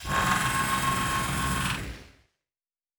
pgs/Assets/Audio/Sci-Fi Sounds/Mechanical/Servo Big 5_1.wav at master
Servo Big 5_1.wav